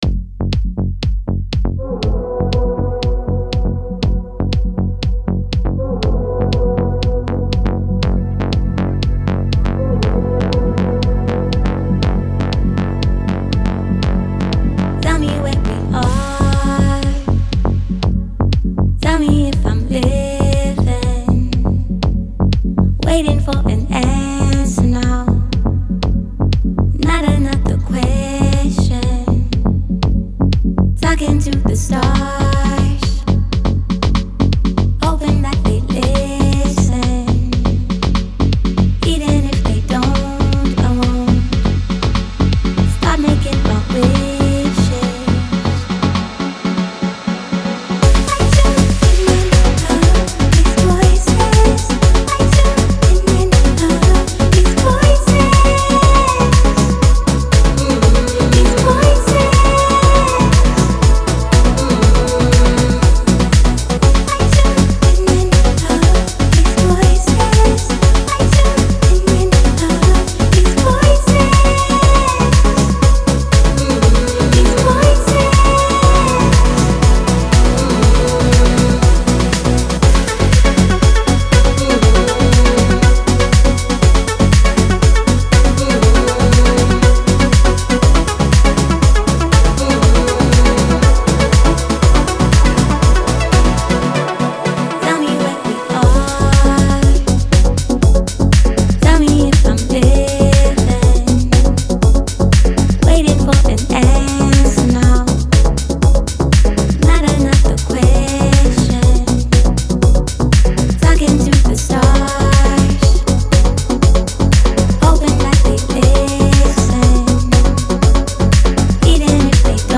яркие синтезаторы, ритмичные биты и запоминающийся вокал